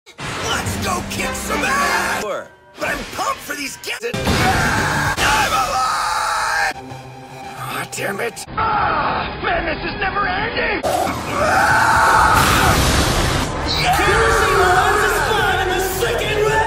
kirishima screaming for 16.6 seconds sound effects free download